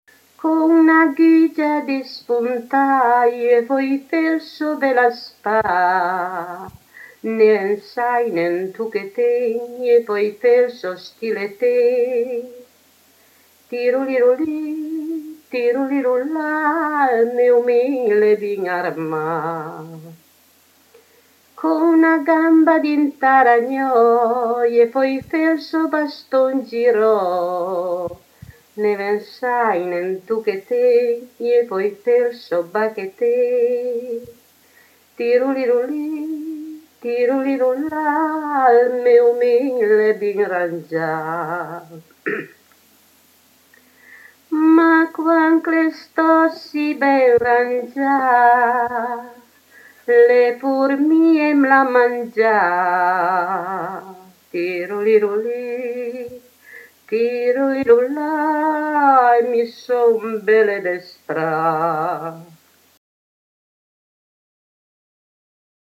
Il maritino / [registrata a Cossano Belbo (CN)